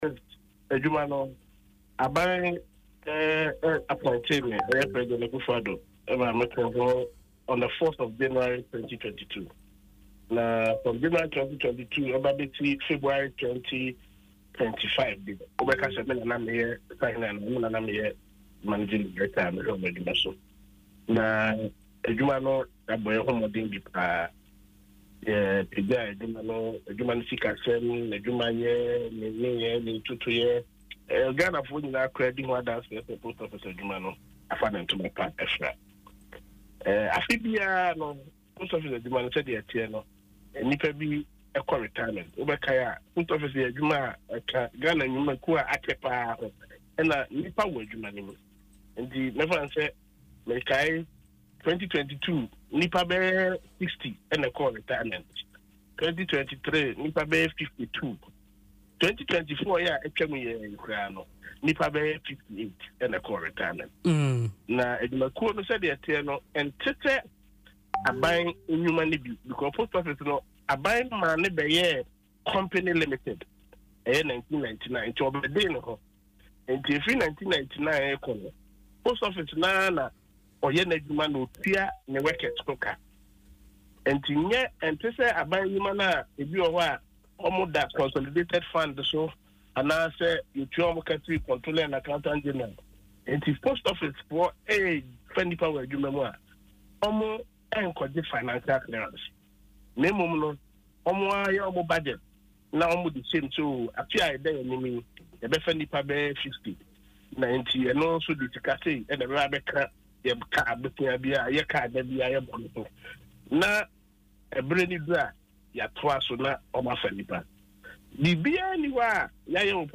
Reacting to the development in an interview on Adom FM’s Dwaso Nsem, Obour explained that Ghana Post operates in a way that necessitates annual recruitments to replace retirees.